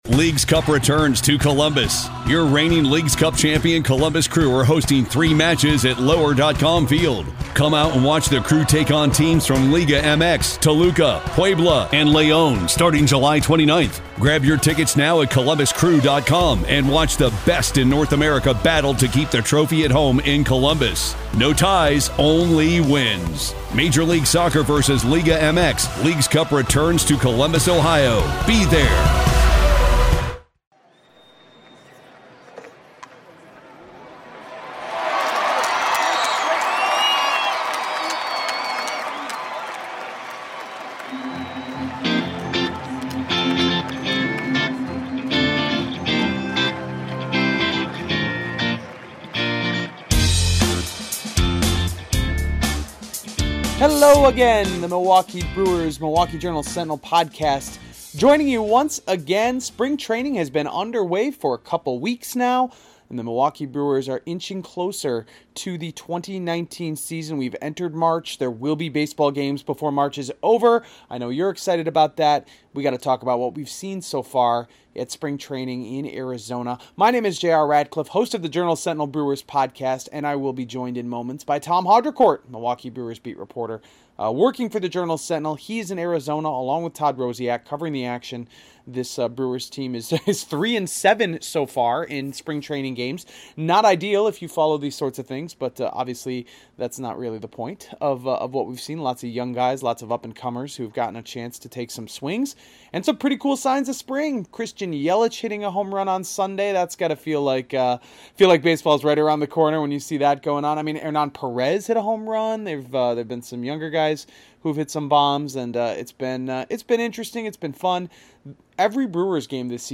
Sound effect: WALLA Ballpark Cheer William Tell Overture Organ